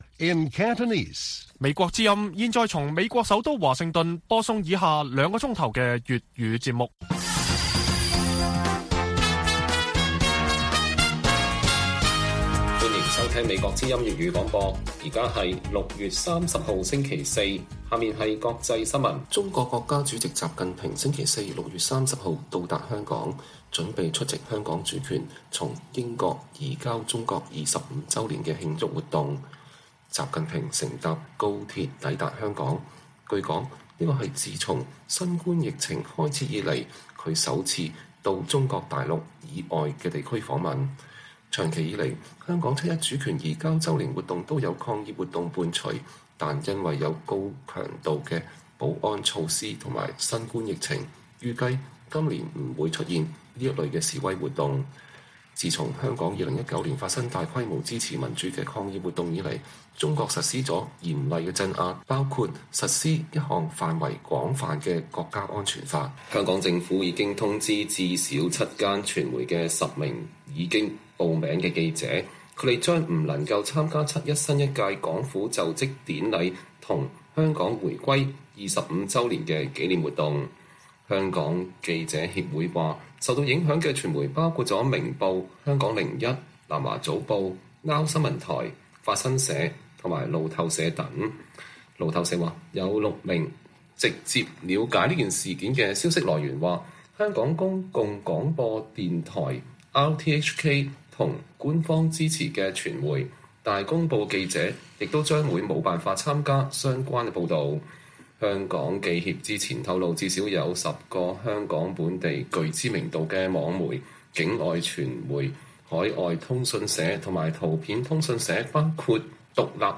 粵語新聞 晚上9-10點: 習近平抵港 七一抗議活動難再現